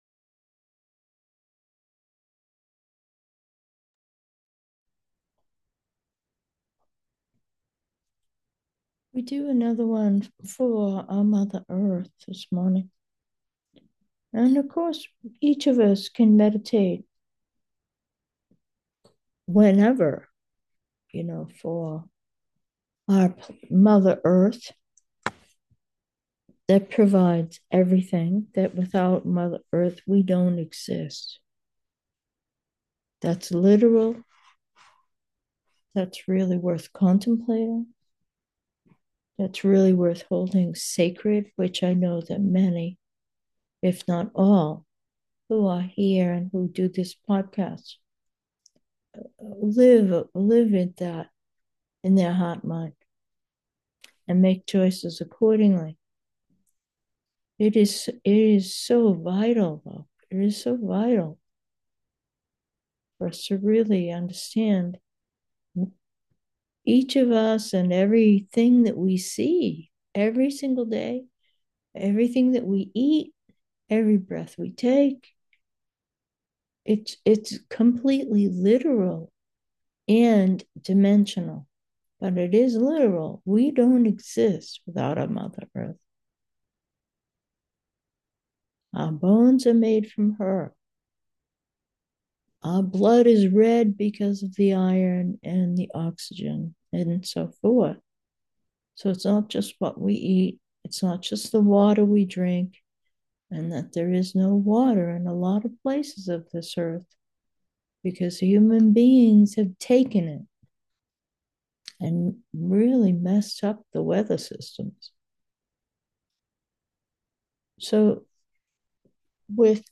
Meditation: with Mother Earth and all relations